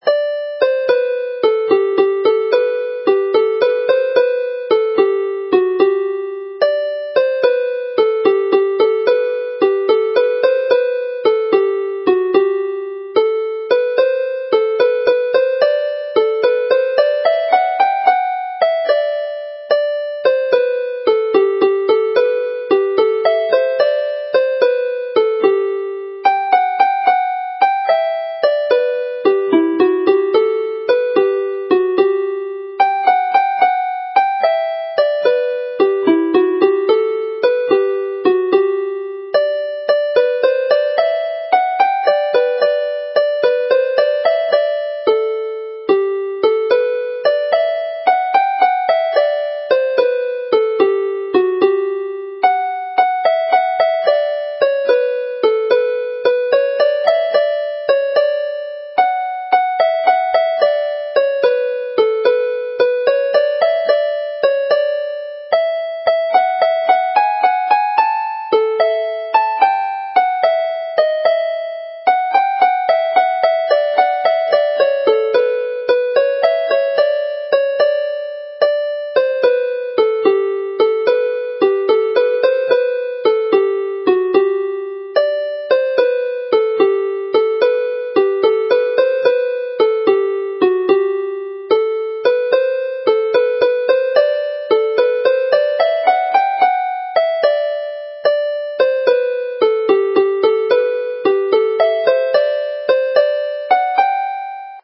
Play the set slowly
This set of jigs is a direct adaptation into 6/8 time of the December 2011 Nos Galan set based on perhaps the most famous Christmas melody of all: Nos Galan, with Glanbargoed and Llwytcoed as supporting tunes in the dance set.